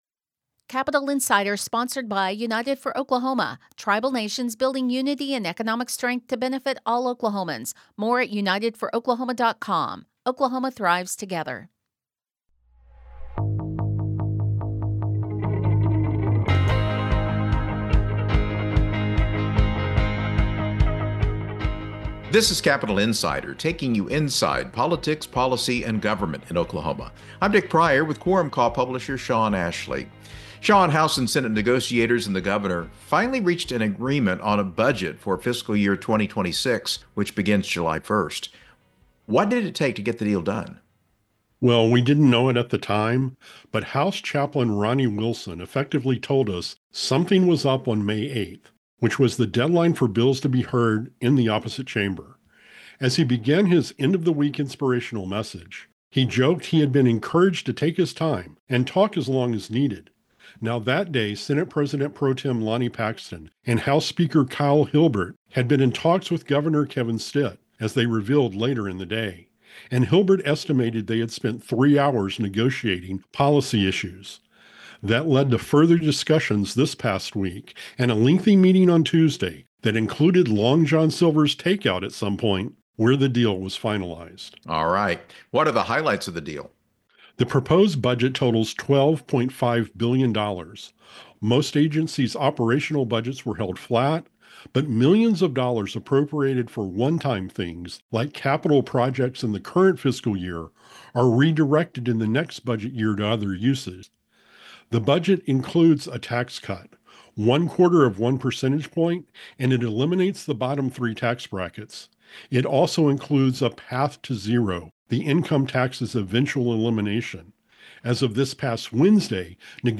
plus interviews with newsmakers.